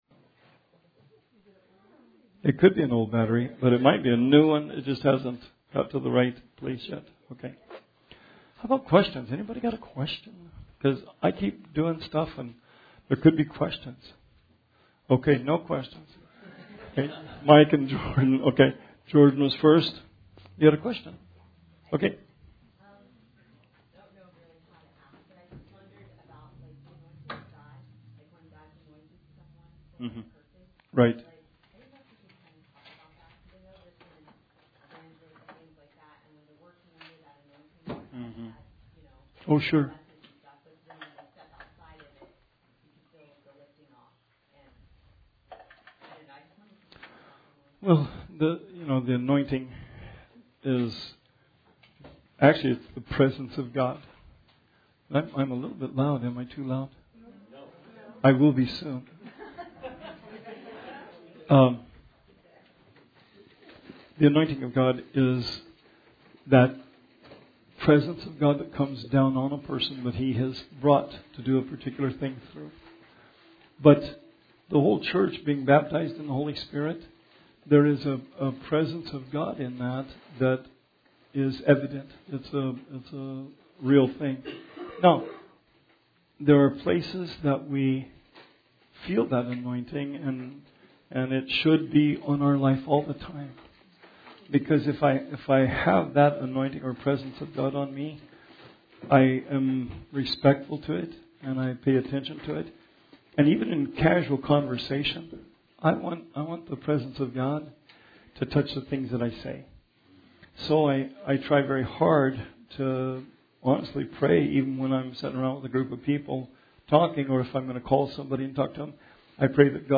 Bible Study 4/17/19